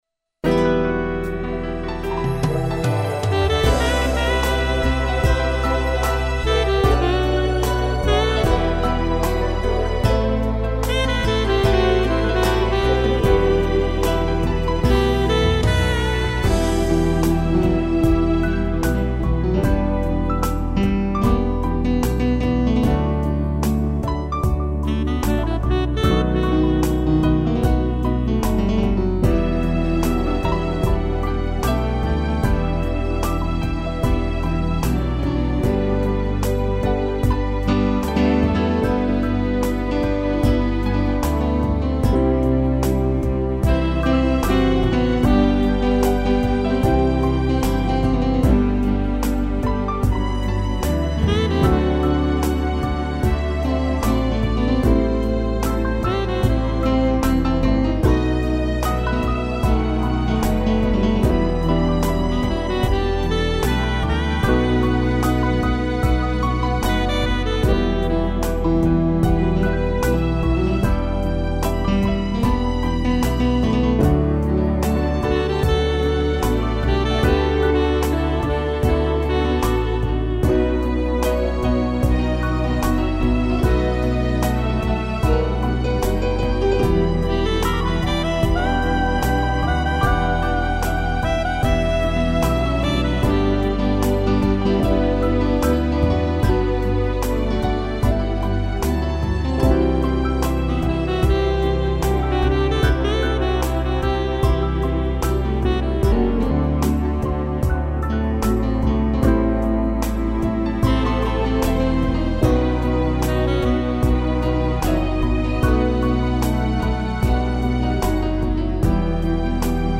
piano, sax, cello e violino
(instrumental)